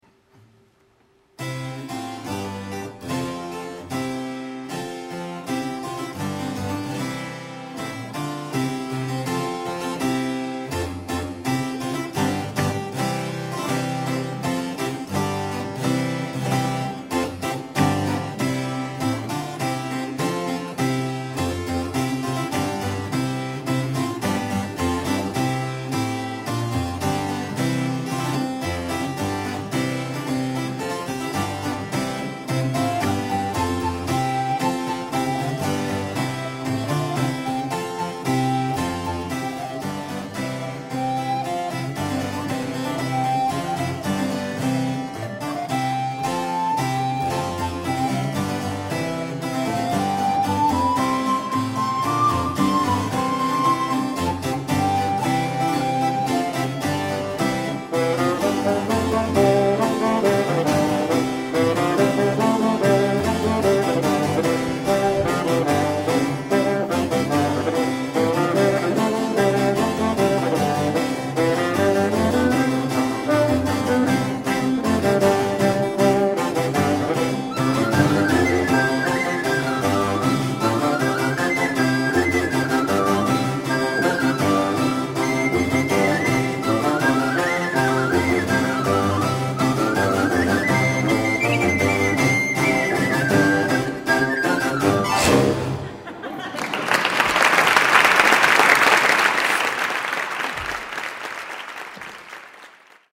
Templo de la Valenciana - Guanajato-Mexico - Festival Internacional Cervantino, 4 ottobre 2007
CONSORT VENETO:
soprano
flauto
Dulciana
cembalo
Registrazione a Cura di Radio Educàtion Mexico
BalloFurlano.mp3